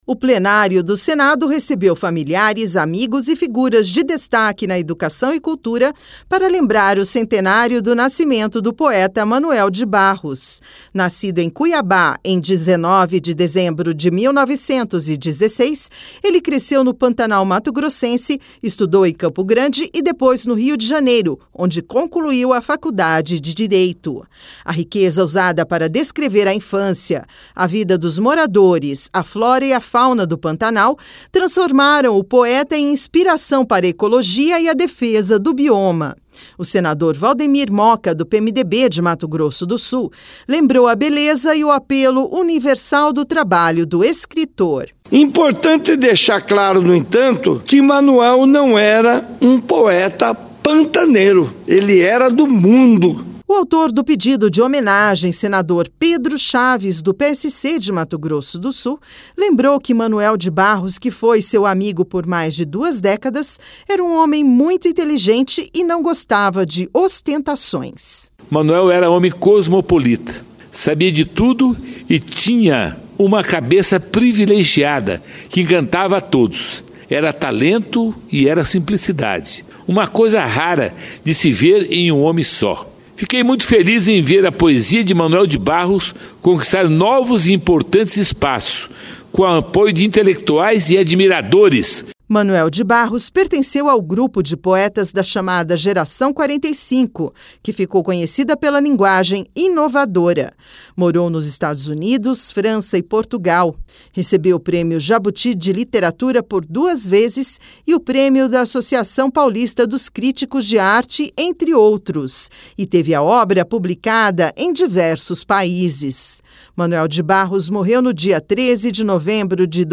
Sessão especial